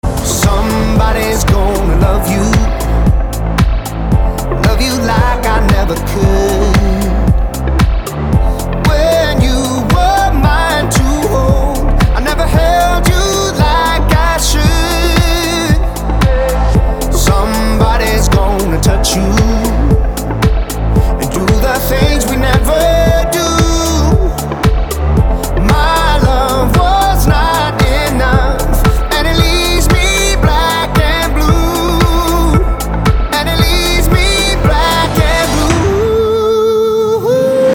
• Качество: 320, Stereo
поп
мужской вокал
dance
спокойные